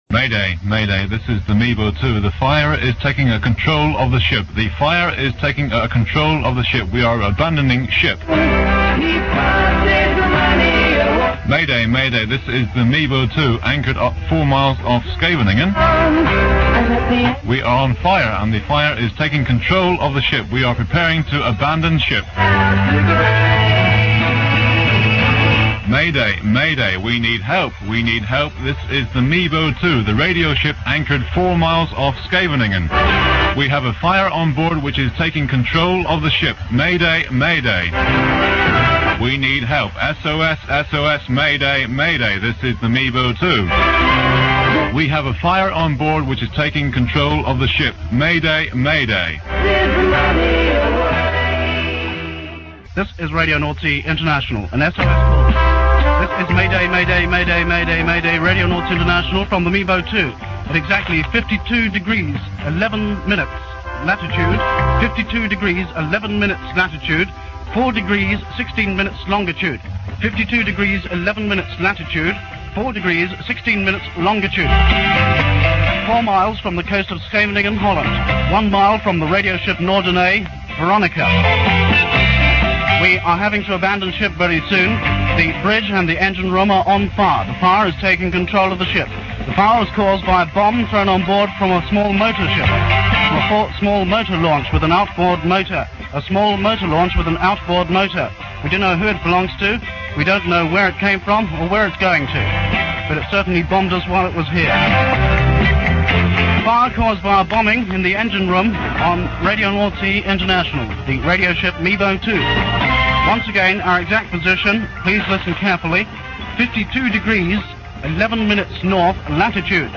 This time they really are abandoning ship. This and the previous clips are edited from a recording made available by The Offshore Radio Archive (duration 4 minutes 7 second)